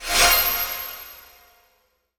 magic_light_bubble_05.wav